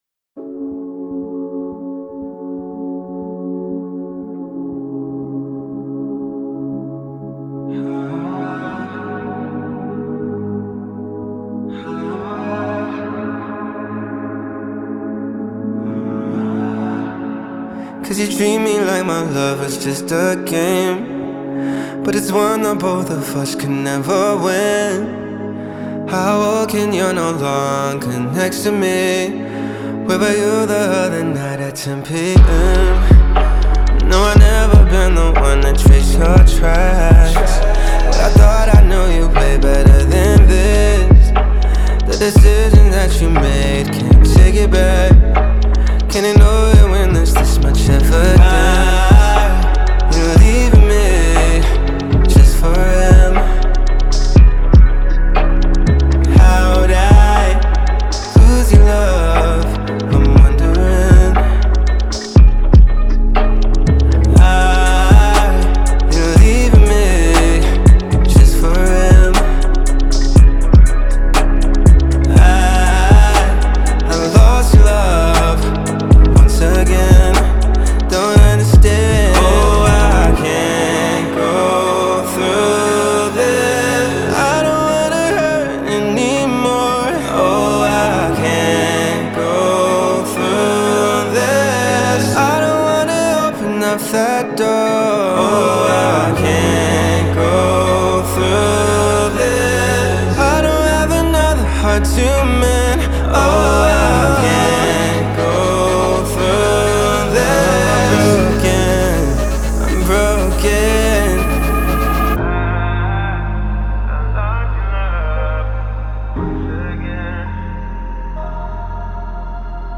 • Жанр: Soul, R&B